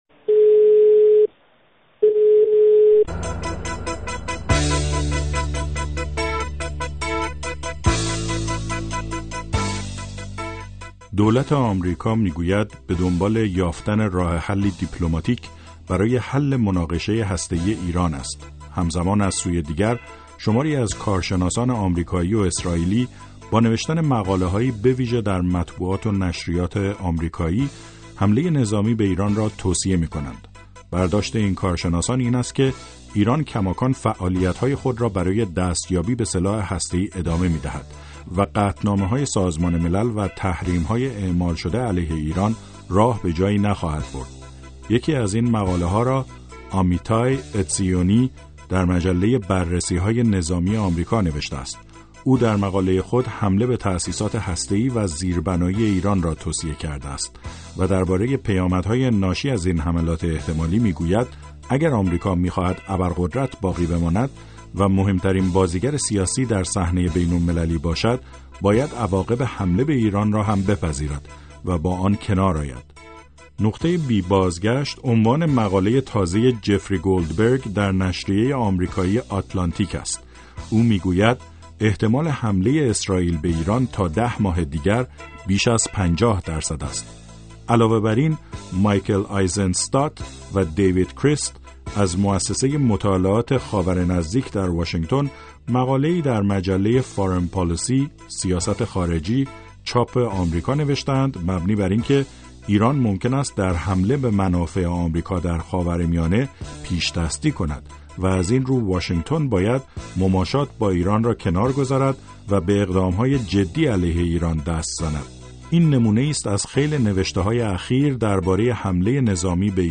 گفت‌وگوی ویژه با منصور فرهنگ نماینده پیشین ایران در سازمان ملل و کارشناس روابط بین الملل